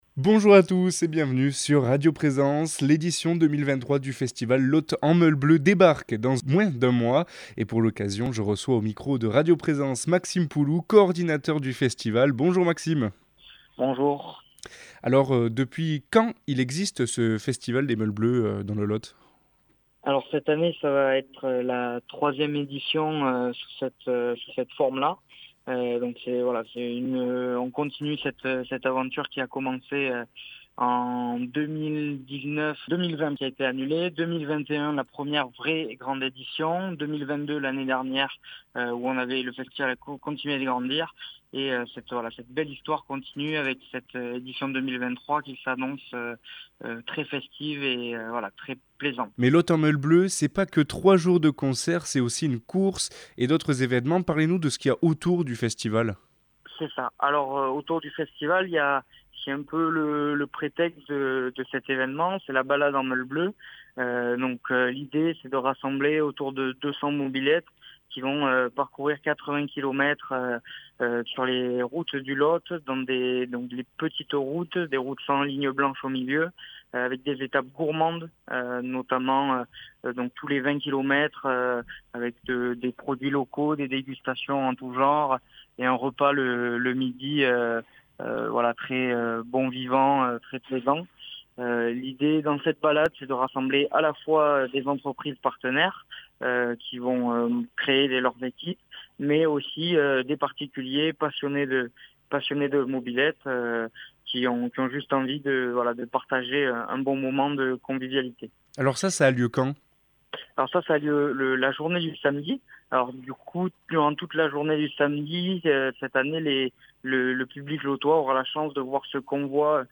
Et pour en parler nous recevons au micro de radio présence Cahors